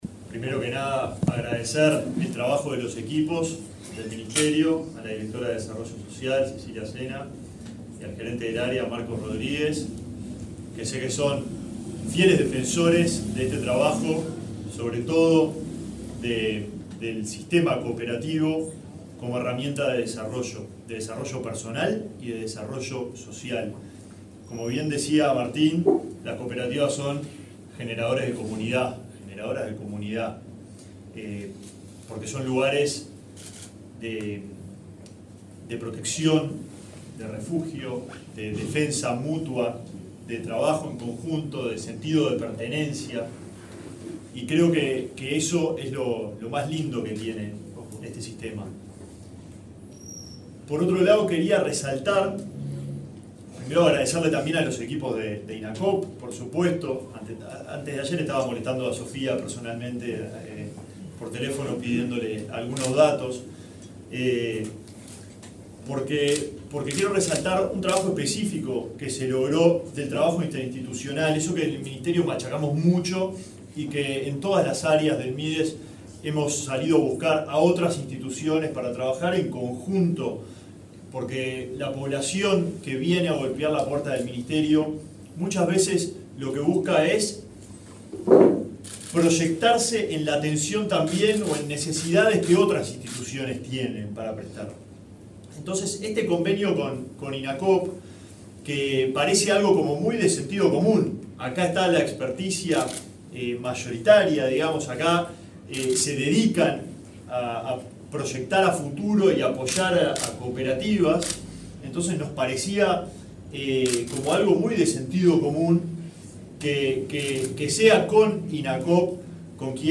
Palabras del ministro de Desarrollo Social, Alejandro Sciarra
Este martes 1.°, el titular del Ministerio de Desarrollo Social, Alejandro Sciarra, participó en la presentación de datos acerca de las cooperativas